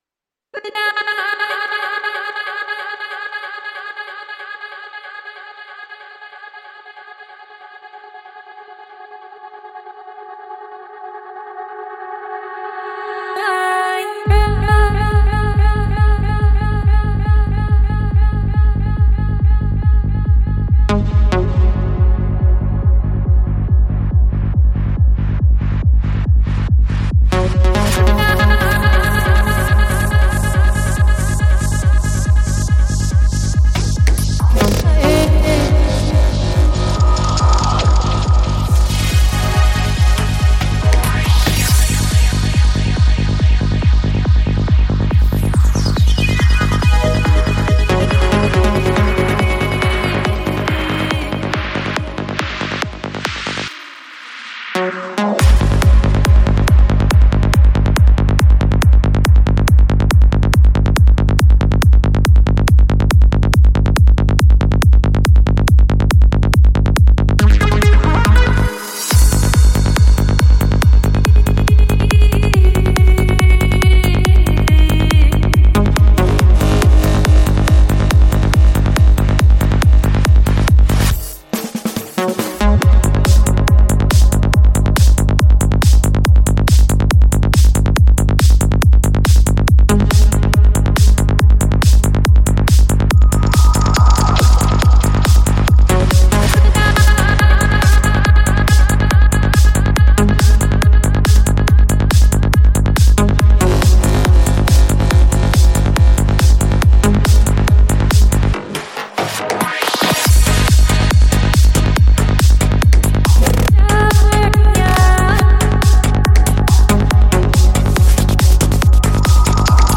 Жанр: Psychedelic
Psy-Trance Скачать 6.76 Мб 0 0 0